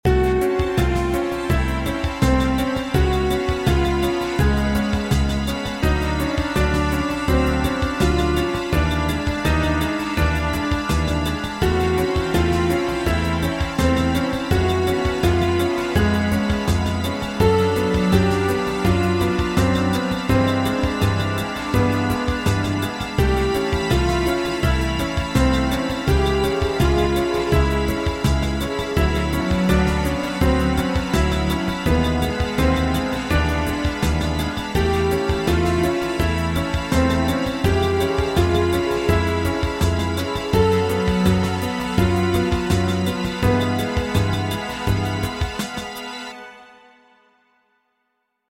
Macabre Playful, Theater Music